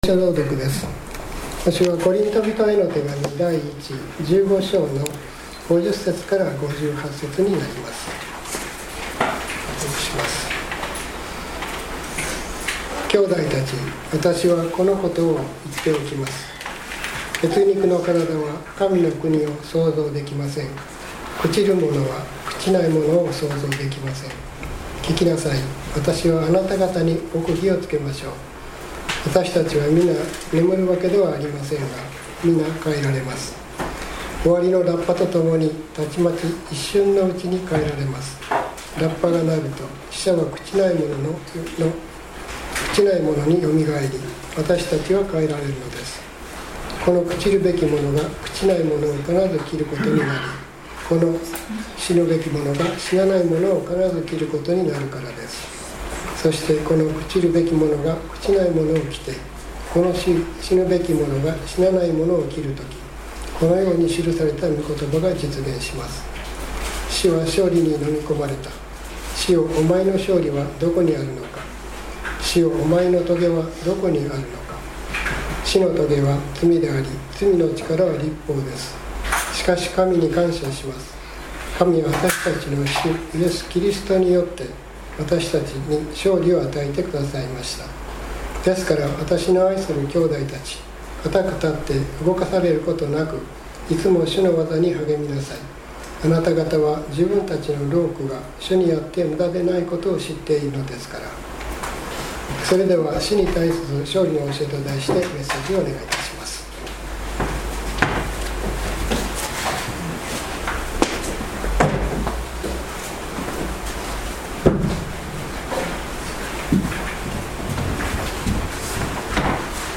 ✾召天者記念礼拝をしました✾
聖書朗読：コリント人への手紙第一１５章５０節～５８節 説教：「死に対する勝利の教え」